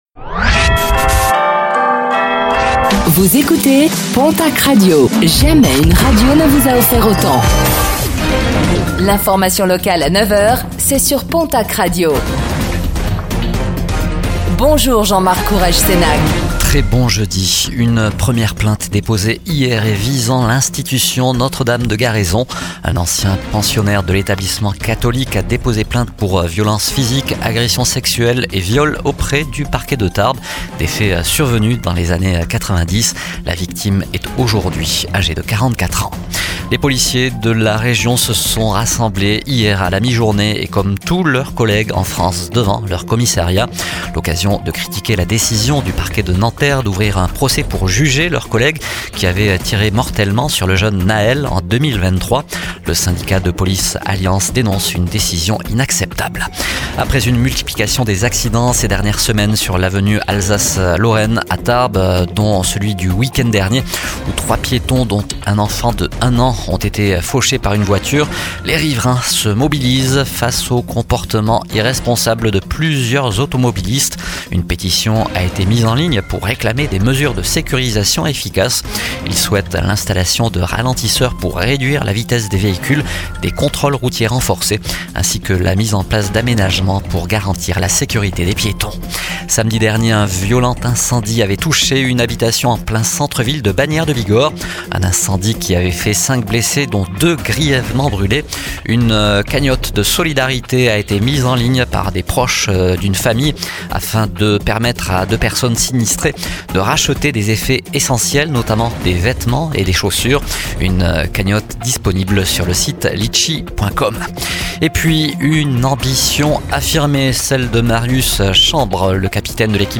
Infos | Jeudi 06 mars 2025